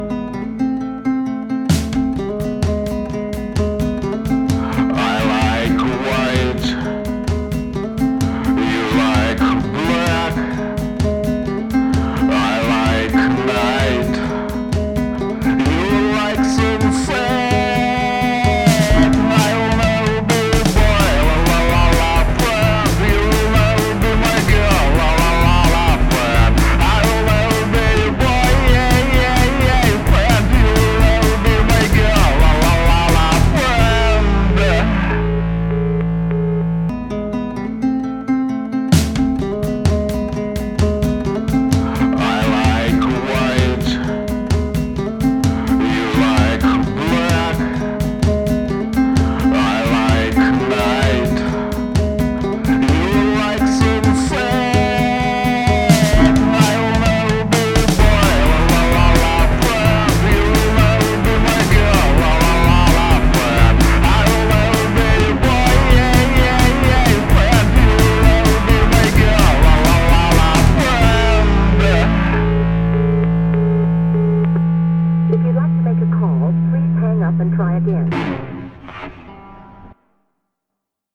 Отрывок первый- наушники, как было изначально, второй - то что корректировал уже на мониторах, а именно: вокал в -2дБ, ударка в начале +2дБ, гитара +2дБ. На мониторах на мой слух нормальный баланс, включаю в наушниках - вокал еле слышно (на припеве), ударка громкая (в начале), гитара громкая)) Где правда?) ПС: и попутно вопрос: в самом начале вокал вроде громко.